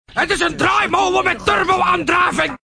Draaimolen Met Turbo Sound Effect Free Download
Draaimolen Met Turbo